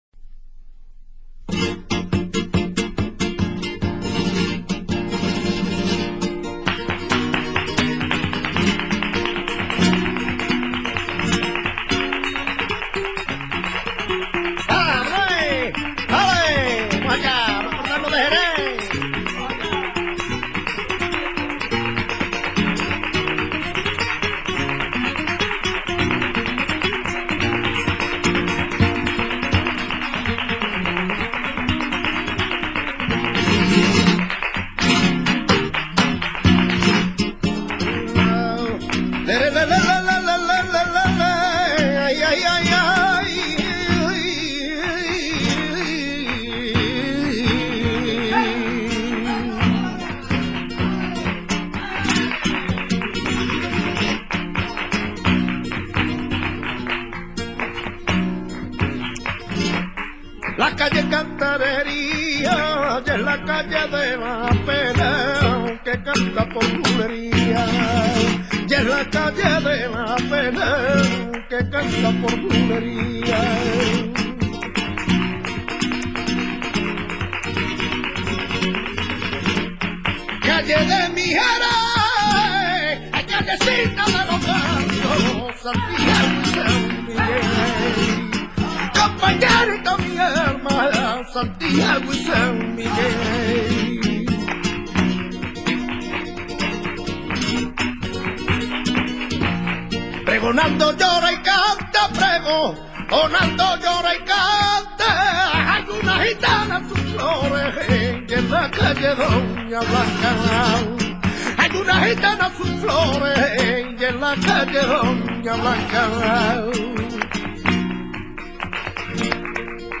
Sonidos y Palos del Flamenco
buleria.mp3